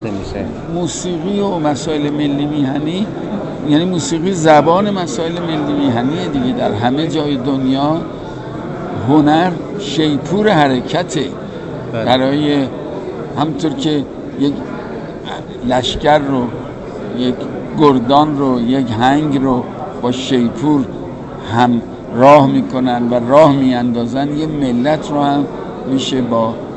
حداد عادل در گفت‌وگو با ایکنا:‌